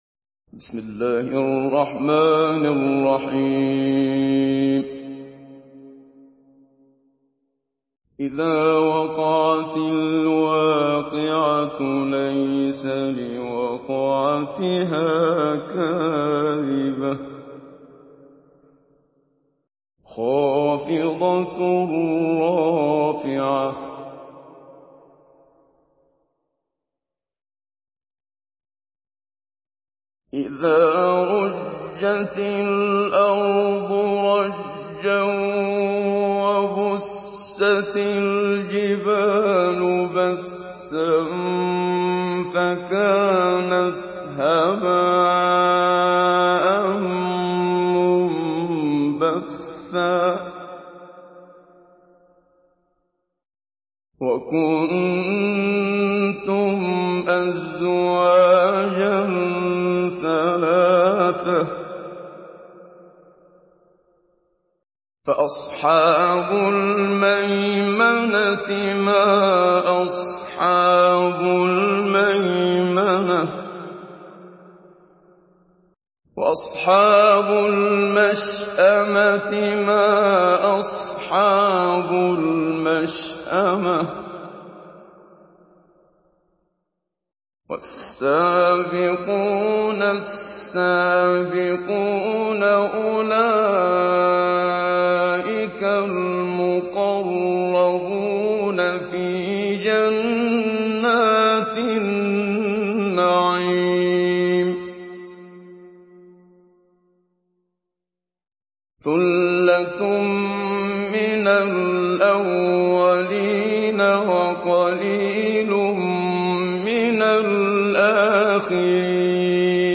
Surah الواقعه MP3 by محمد صديق المنشاوي مجود in حفص عن عاصم narration.
مجود